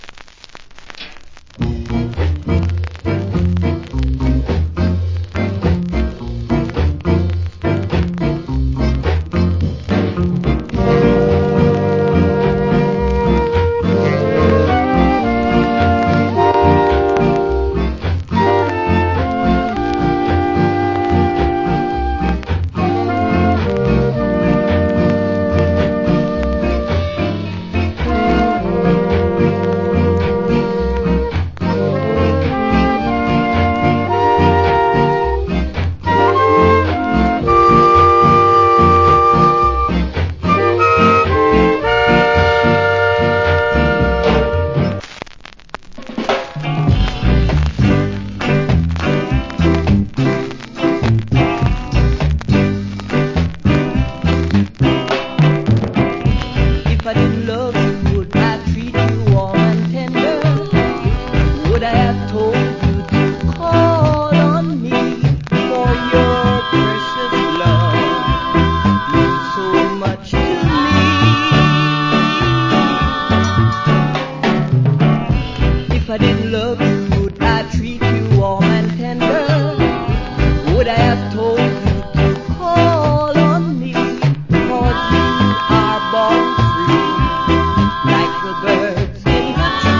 Cool Rock Steady Inst.